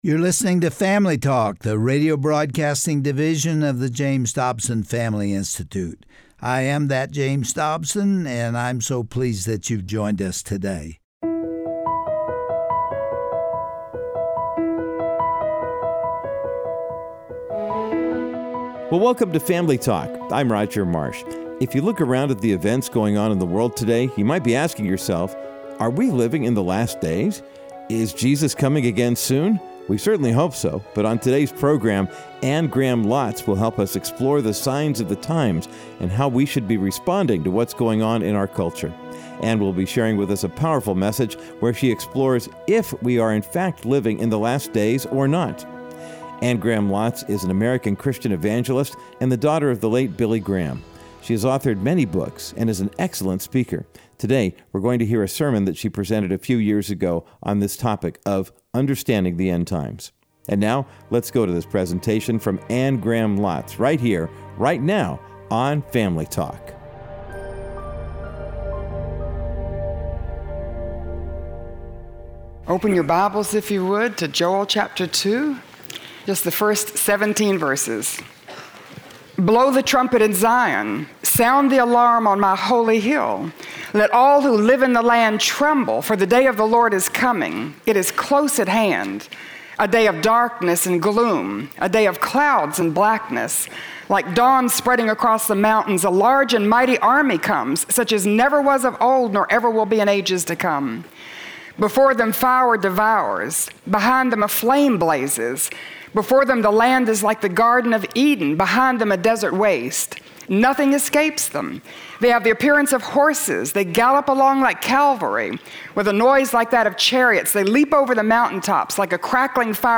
On today’s classic edition of Family Talk, we’ll hear from Anne Graham Lotz as she explores the signs of the times that we are living in. Anne sheds light on scriptural verses that many biblical scholars would deem prophetic.